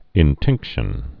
(ĭn-tĭngkshən)